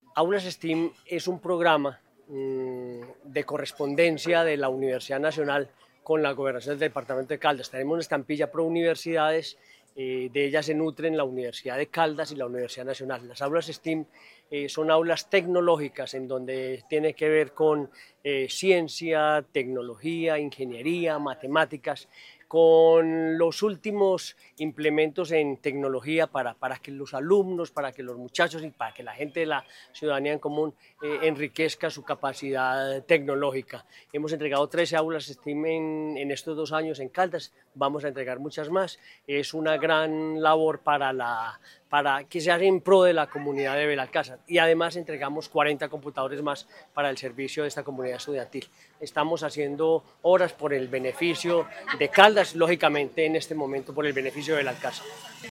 Gobernador de Caldas, Henry Gutiérrez Ángel.
Gobernador-de-Caldas-Henry-Gutierrez-Angel-entrega-computadores-Belalcazar.mp3